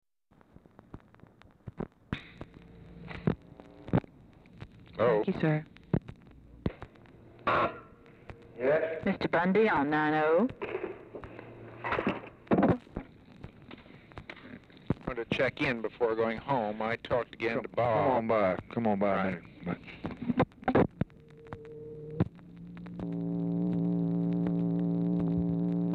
Telephone conversation # 4406, sound recording, LBJ and MCGEORGE BUNDY, 7/29/1964, 7:30PM | Discover LBJ
Format Dictation belt
Location Of Speaker 1 Oval Office or unknown location
Specific Item Type Telephone conversation